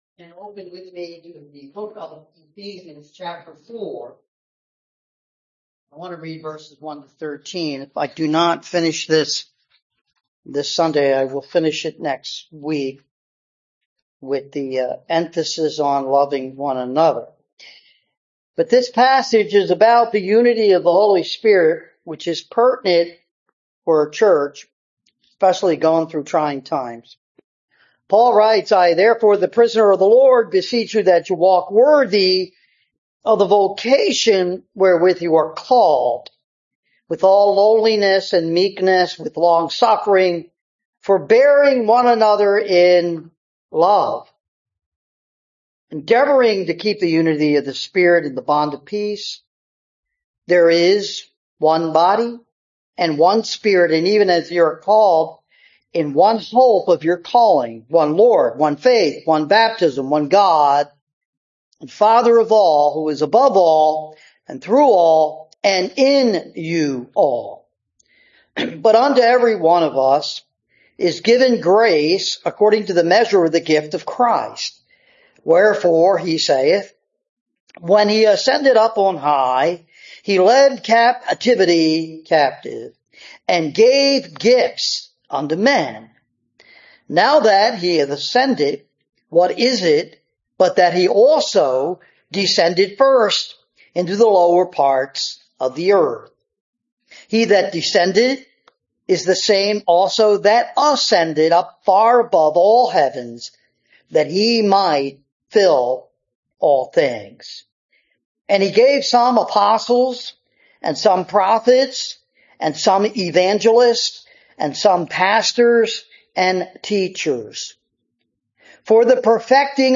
Service Type: Sunday Morning
sermon-August-3-2025.mp3